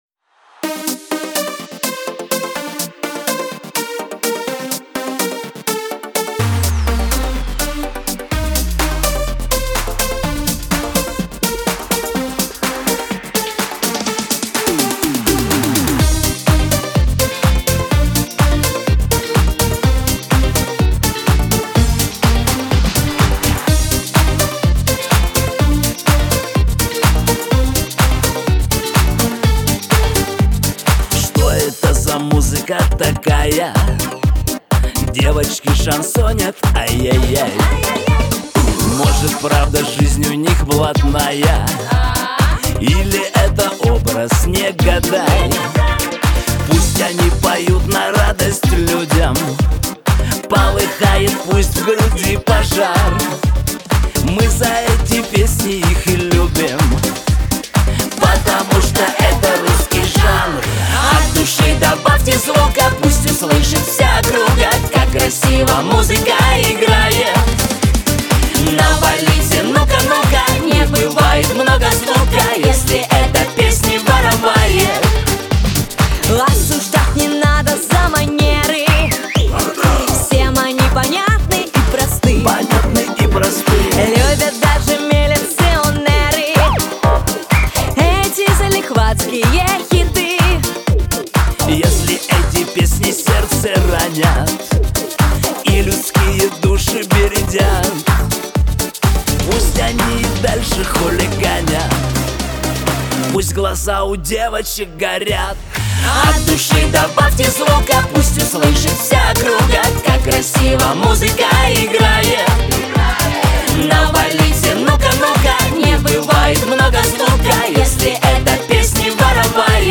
дуэт
Шансон , Лирика